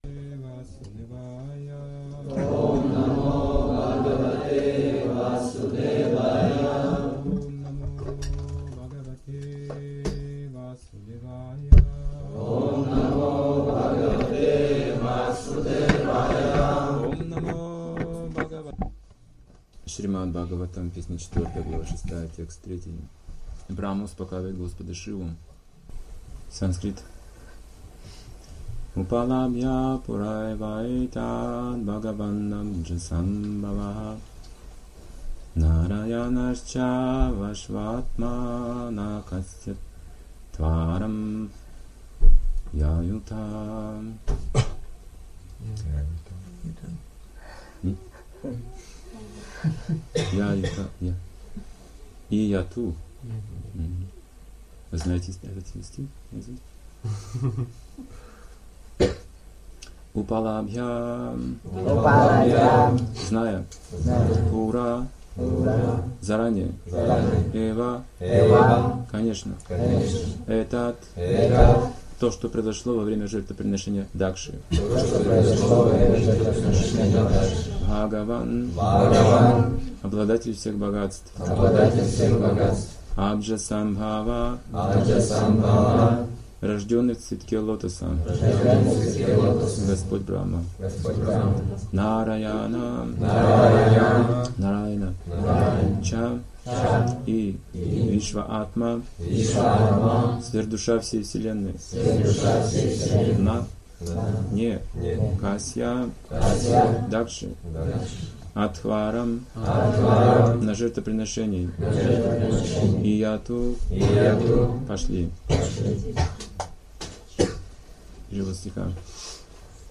Темы, затронутые в лекции